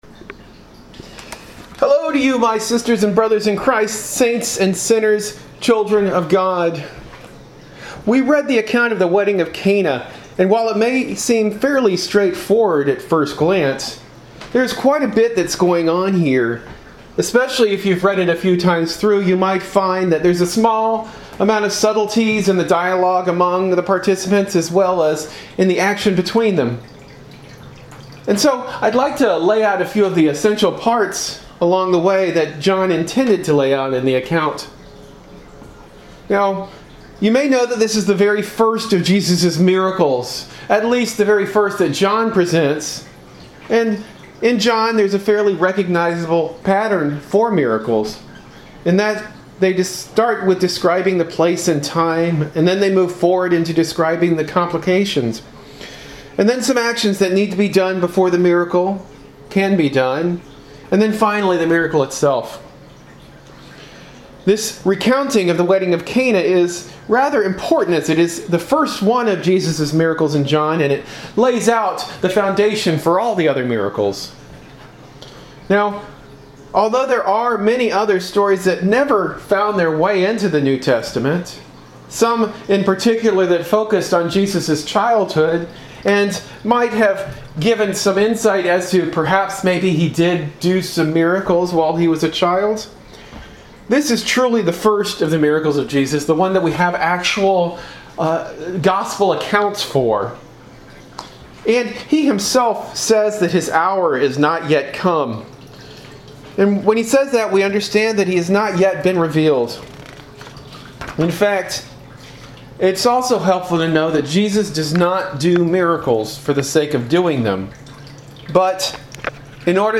Sermon delivered at Lutheran Church of the Cross in Berkeley.